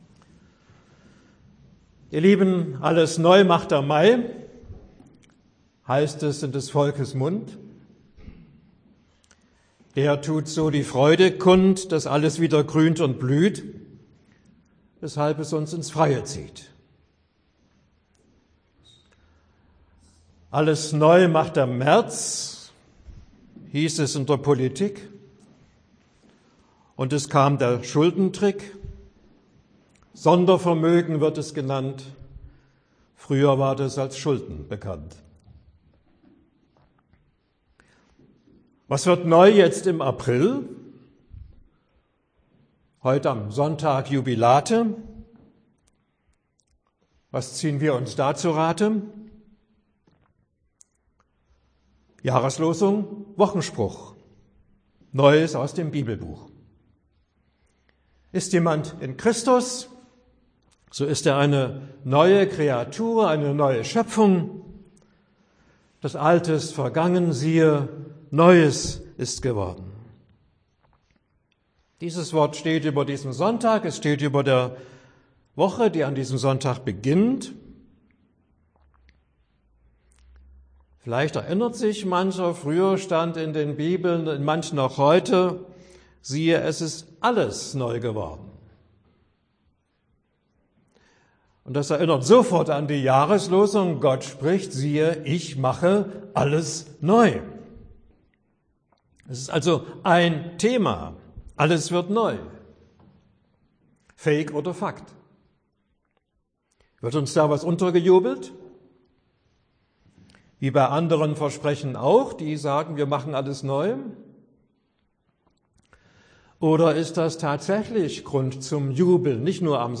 Predigt zum Sonntag Jubilate 2026 (Wochenspruch Jubilate + Jahreslosung 2026)